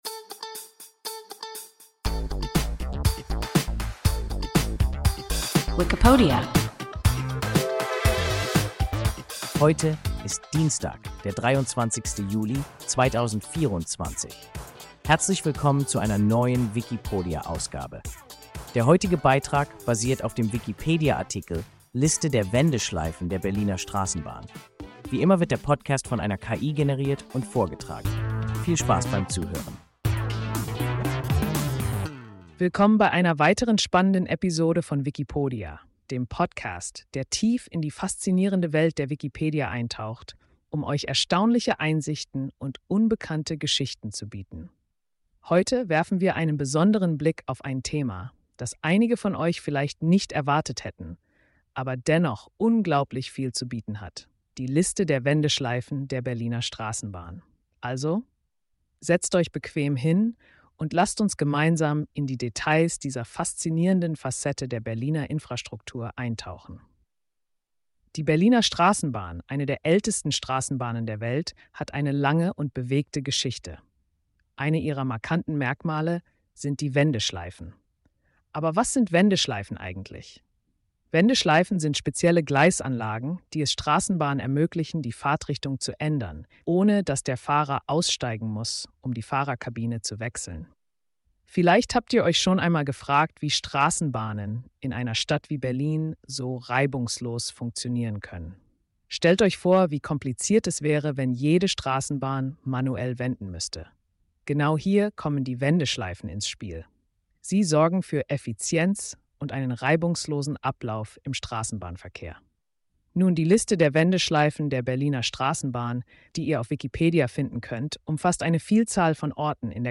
Liste der Wendeschleifen der Berliner Straßenbahn – WIKIPODIA – ein KI Podcast